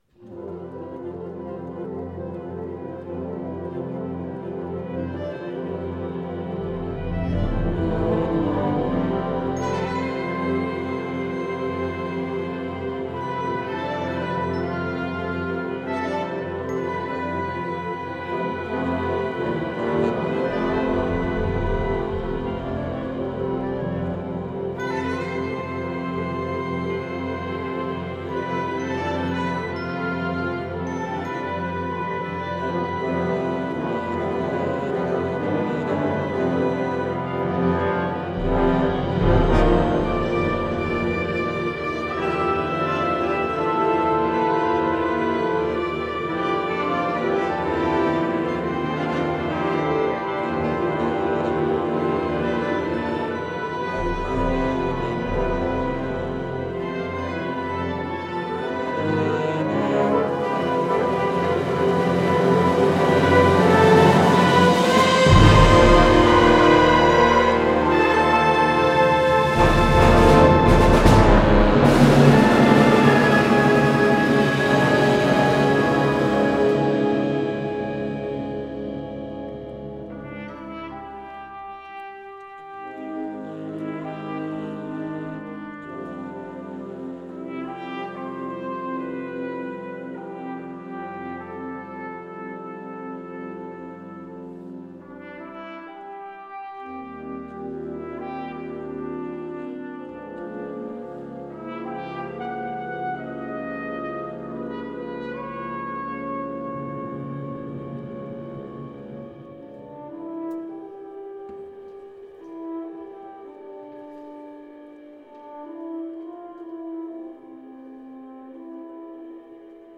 Kirchenkonzert 2017
Er meinte dies natürlich musikalisch und bezog sich auf die vier hoch-emotionalen Blasmusikwerke, die der Musikverein auf dem Konzertprogramm hatte.
Die sanften Passagen, die pulsierenden Steigerungen, die kräftigen, warmen Tutti, die teils schrillen, schmetternden Schreie
Durch das Zusammenspiel beider Hymnen verspricht das Werk Foundation eine tiefgründige und eindrückliche Verarbeitung der Hoffnung, welche mal intim, mal furios klingt.